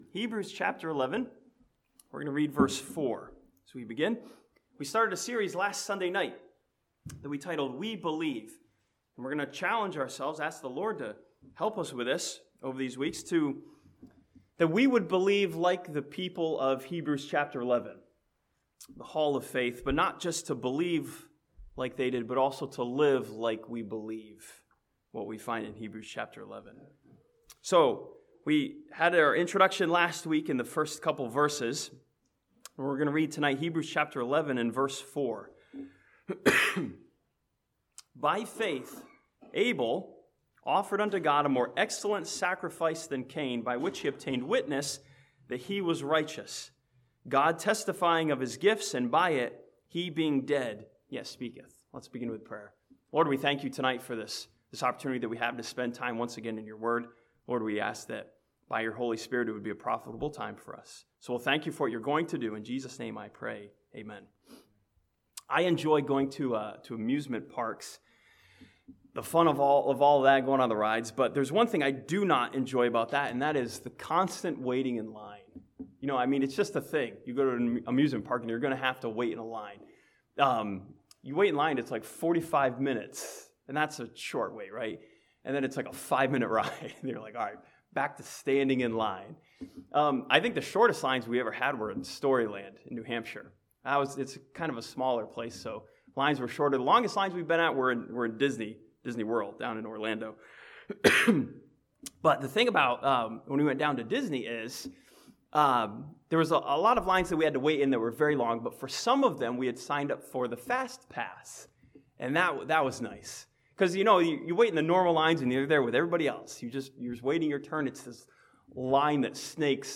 This sermon from Hebrews chapter 11 and Genesis chapter 4 studies what Abel believed and how he showed his faith.